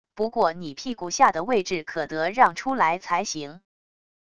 不过你屁股下的位置可得让出来才行wav音频生成系统WAV Audio Player